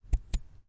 计算机 " 启动笔记本电脑
描述：按下笔记本电脑上的开始按钮。